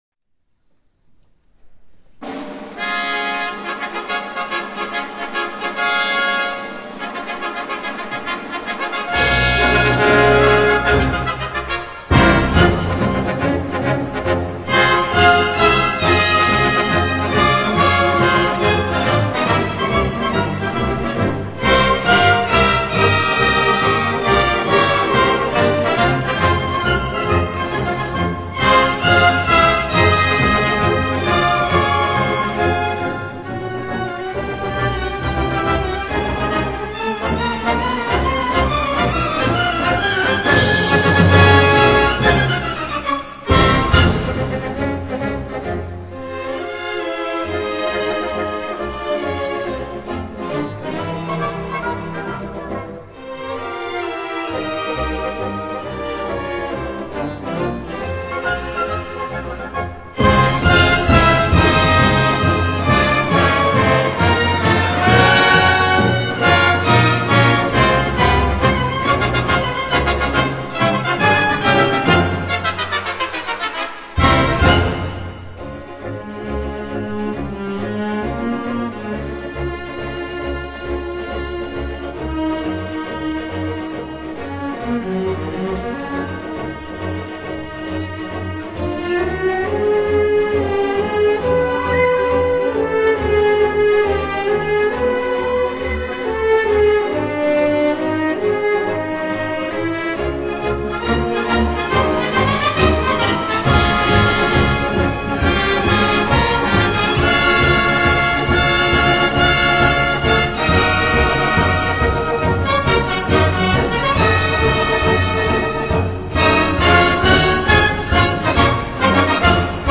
Главная / Песни для детей / Классическая музыка